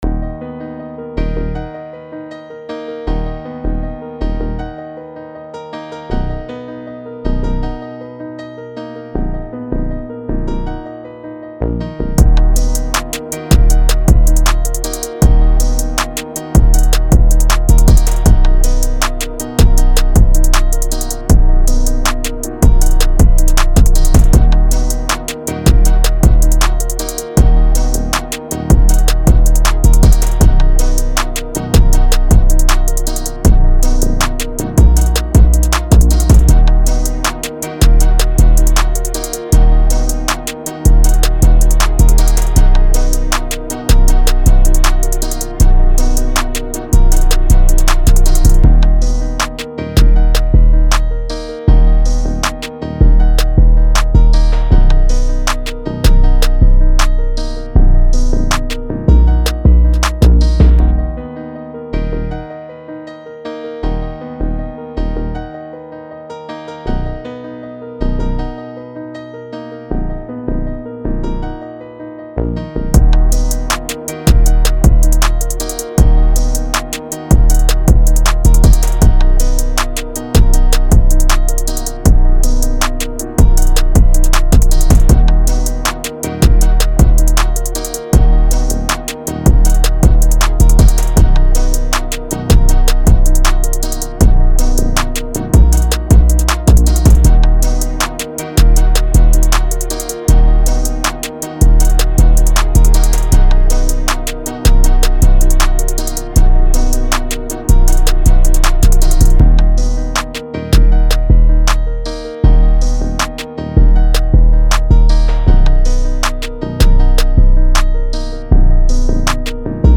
Rap, Hip Hop
d#Minor